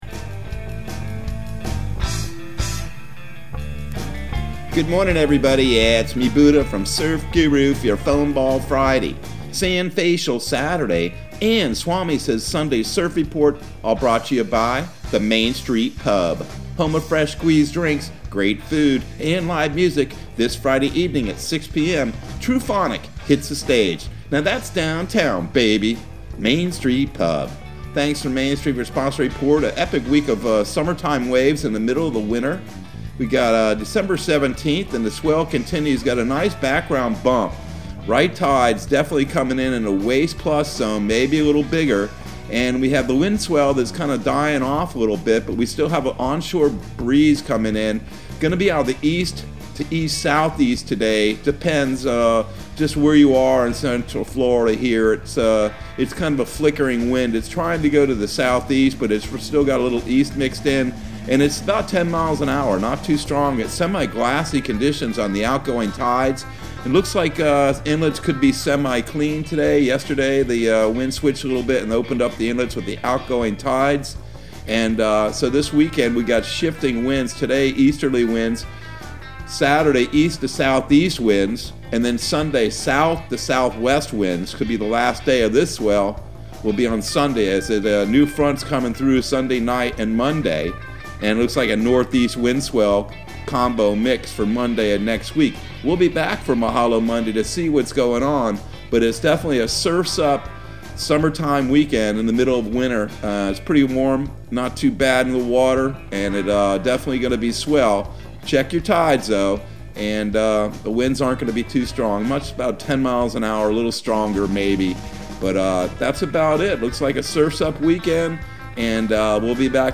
Surf Guru Surf Report and Forecast 12/17/2021 Audio surf report and surf forecast on December 17 for Central Florida and the Southeast.